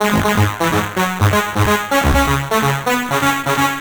Straight Talk Ab 126.wav